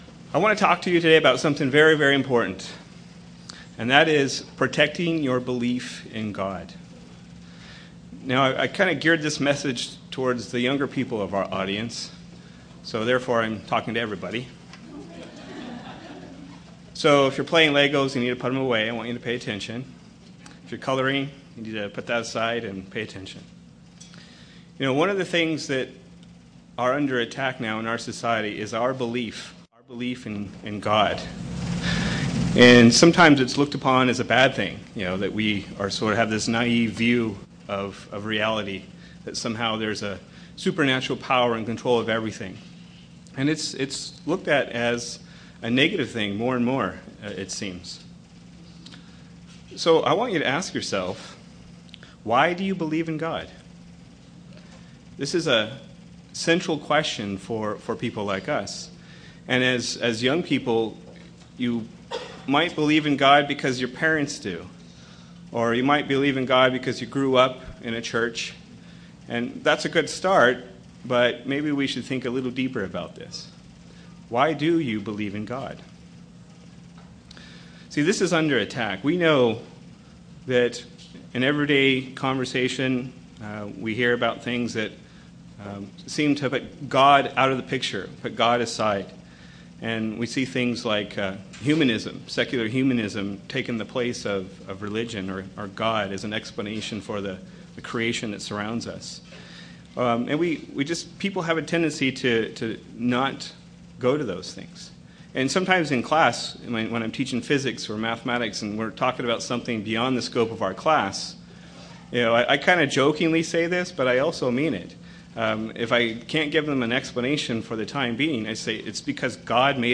Given in Redlands, CA
UCG Sermon Studying the bible?